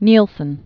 (nēlsən), William Allan 1869-1946.